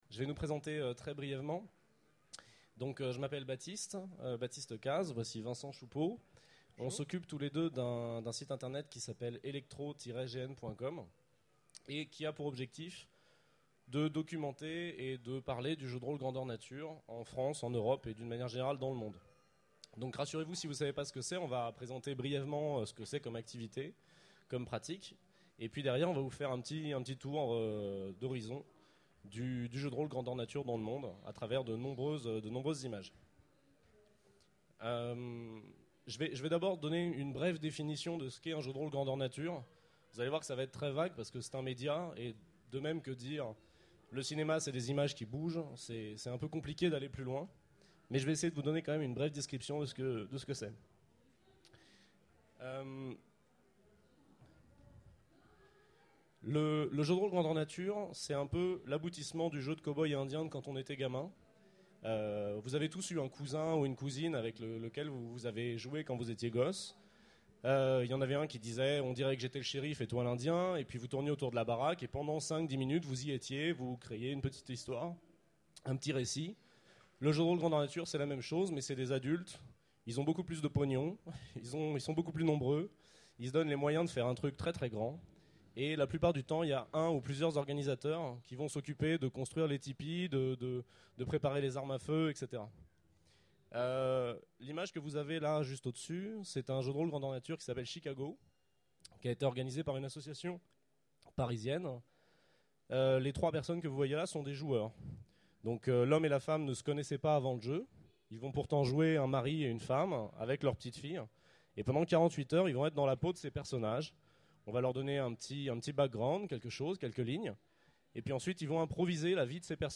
Utopiales 12 : Conférence Jeu de rôle Grandeur Nature